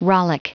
Prononciation du mot rollick en anglais (fichier audio)
Prononciation du mot : rollick